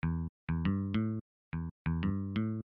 描述：为Rythem、Chill out、Hip Hop和Trance提供酷的伴奏低音。
Tag: 90 bpm Chill Out Loops Bass Loops 459.50 KB wav Key : Unknown